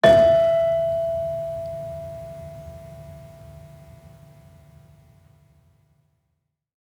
Gender-1-E4-f.wav